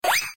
slide.mp3